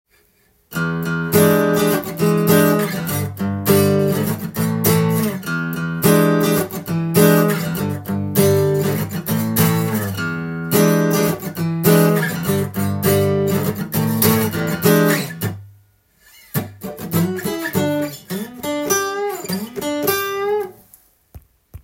Eコードの小節のリズムがどこかで聞いたことある有名なものです。